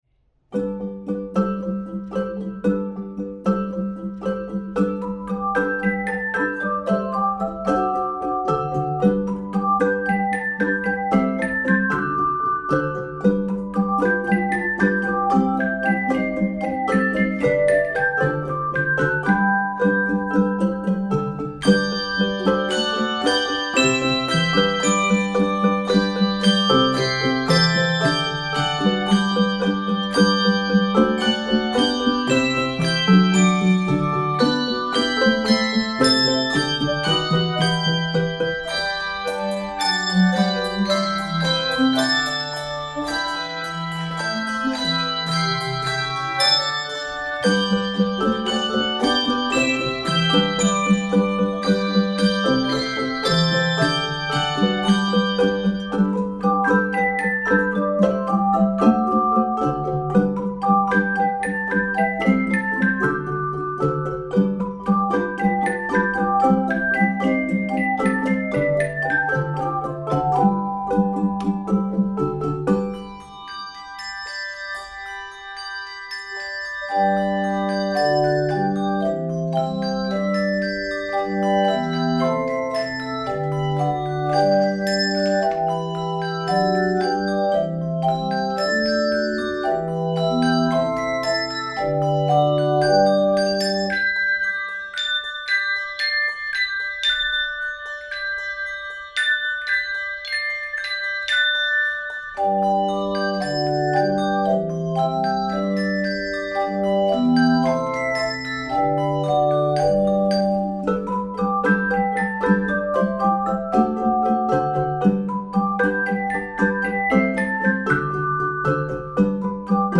Voicing: 3-5 Octave Handbells and Handchimes